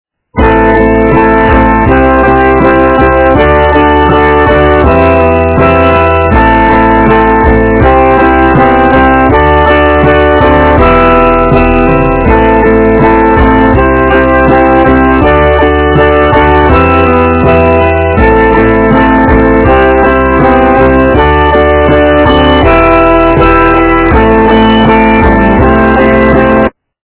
рок, металл
полифоническую мелодию